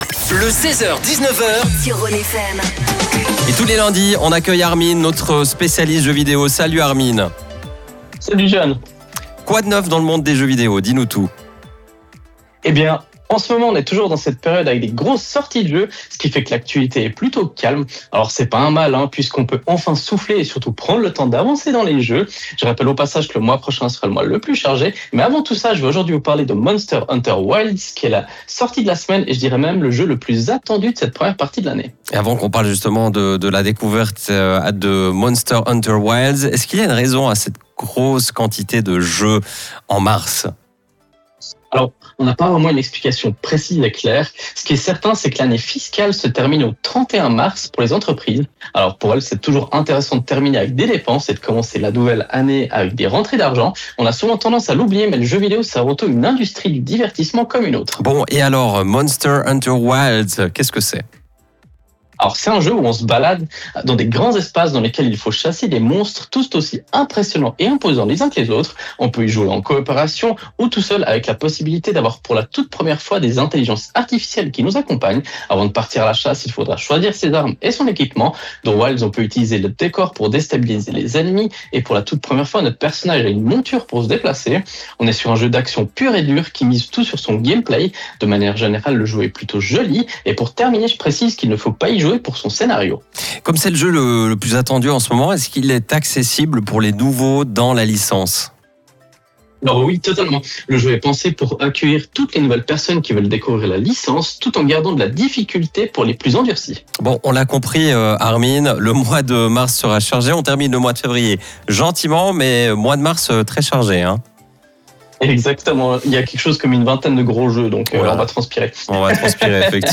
Comme tous les lundis, nous avons la chance de présenter notre chronique gaming sur la radio Rhône FM. Une capsule gaming qui retrace l’actualité du moment.